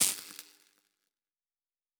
Spark 18.wav